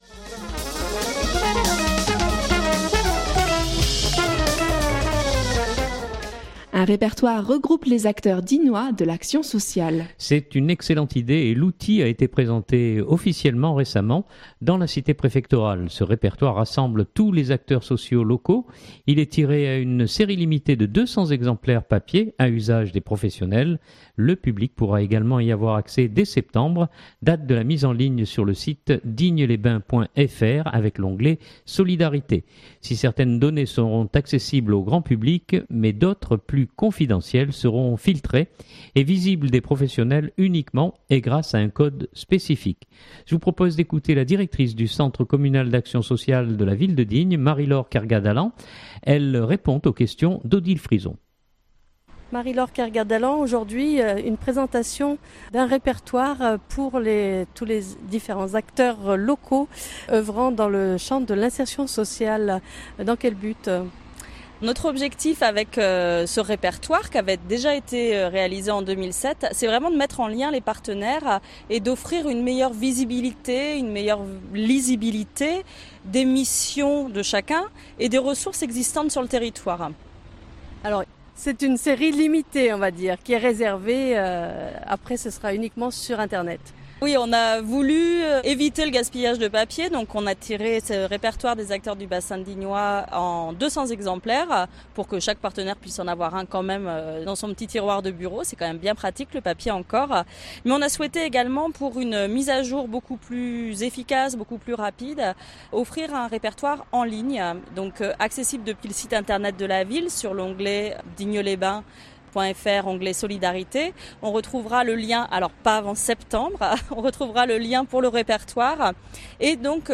Elle répond aux questions